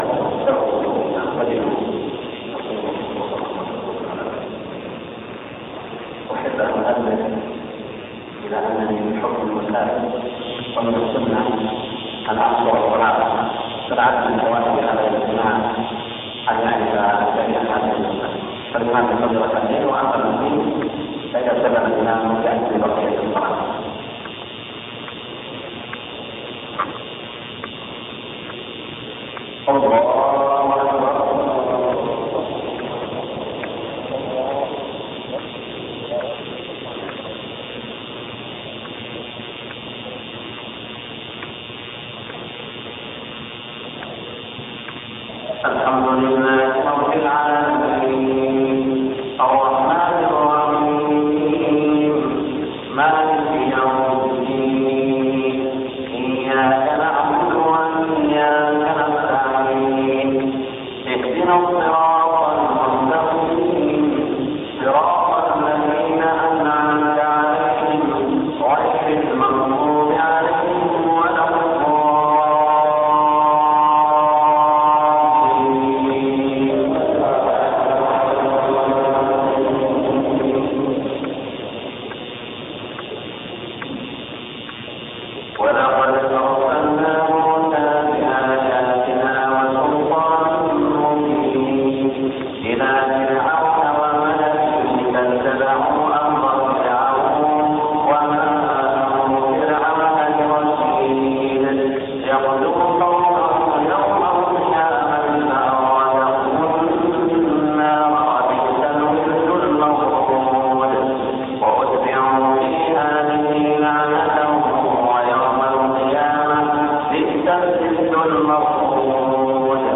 صلاة العشاء 1415هـ في جده من سورة هود 96-109 > تلاوات الشيخ سعود الشريم خارج الحرم > تلاوات و جهود الشيخ سعود الشريم > المزيد - تلاوات الحرمين